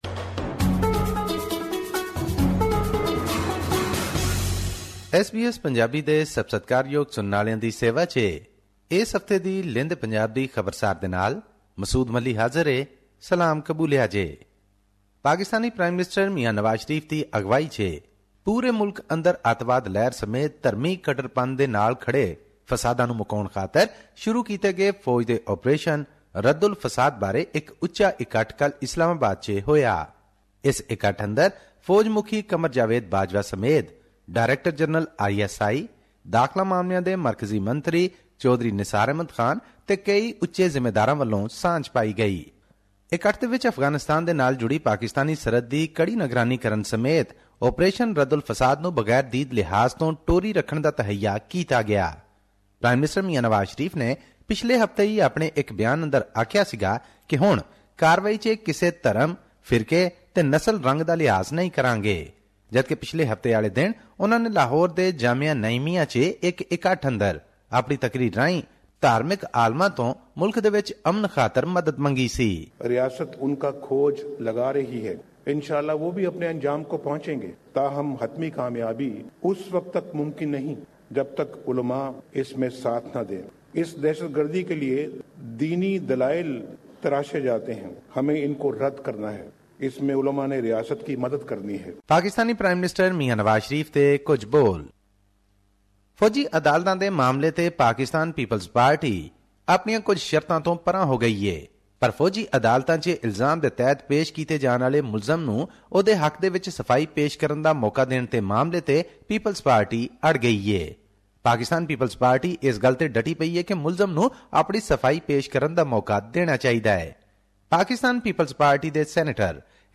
Weekly report from West Punjab, Pakistan Source: SBS Punjabi